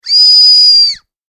フエフキラムネ1.mp3